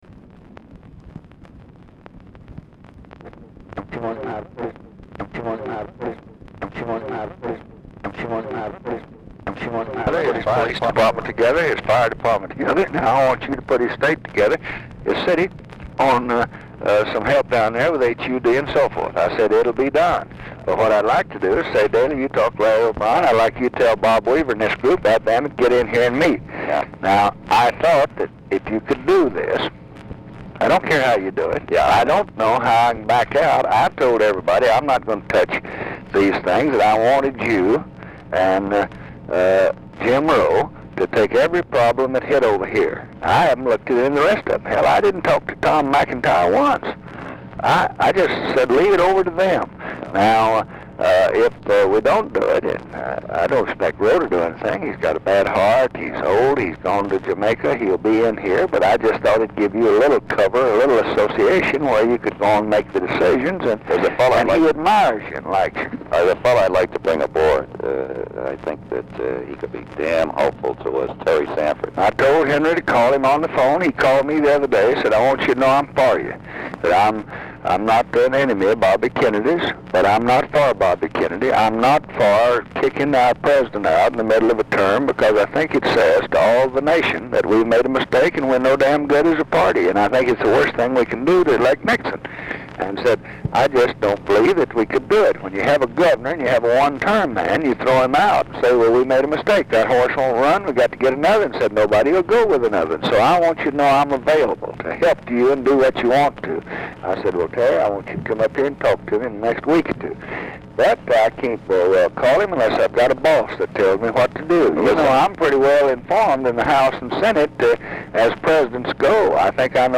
Telephone conversation # 12840, sound recording, LBJ and LARRY O'BRIEN, 3/23/1968, 12:46PM?